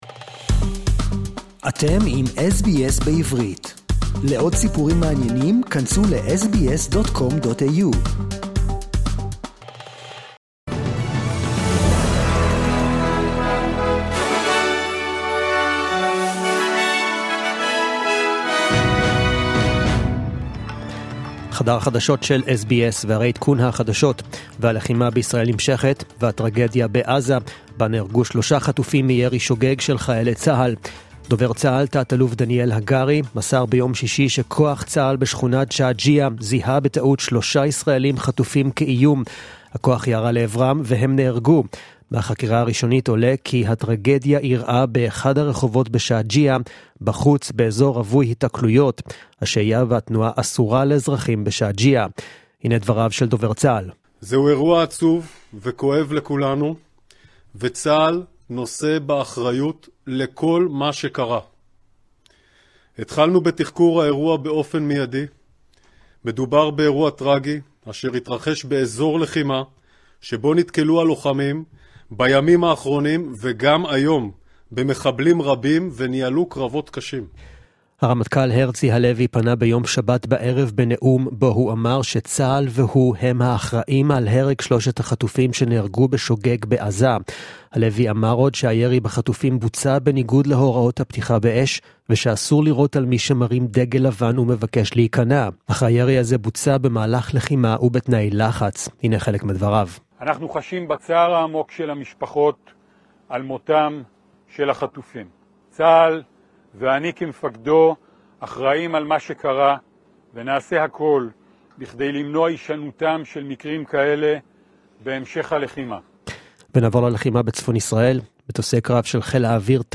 The latest news in Hebrew, as heard on the SBS Hebrew program (17.12.23)